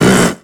Cri de Spoink dans Pokémon X et Y.